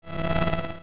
0Science Fiction Sci-Fi Electronic Energy Swell Fluttering Slow 08.wav